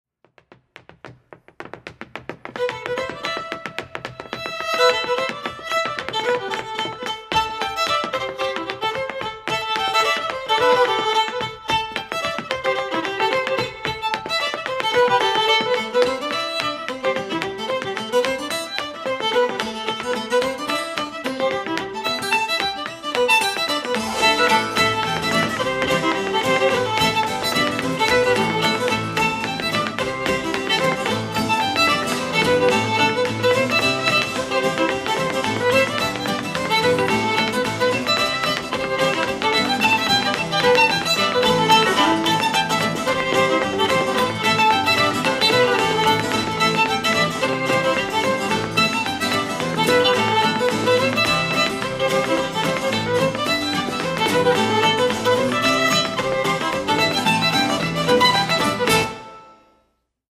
fiddling
reel